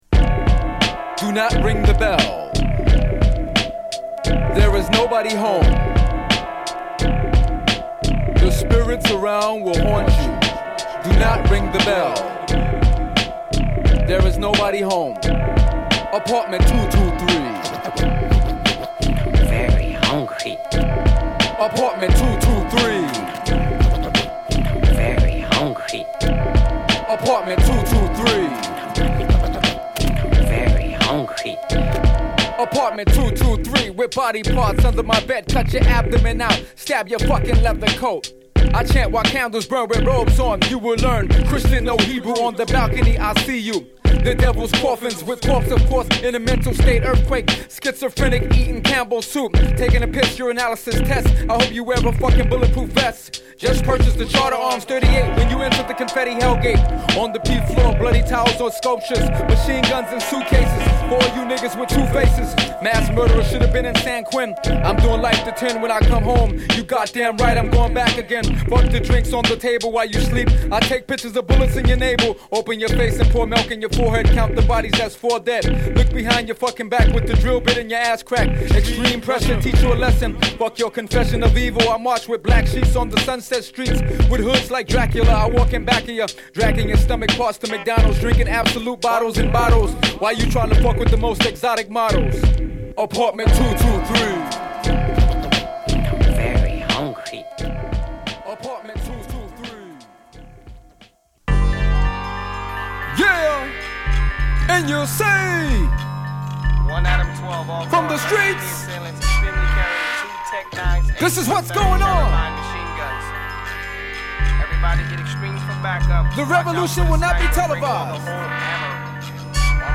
アルバム全体を不穏な空気が支配する中、「イル」と形容されるテクニカルでスキルフルなラップ。